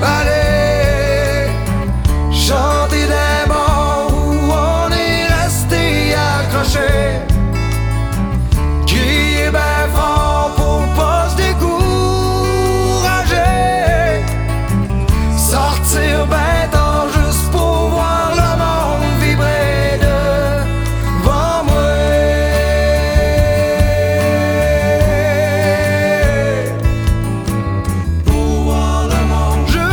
• Musique francophone